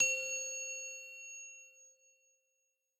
simple-bell.ogg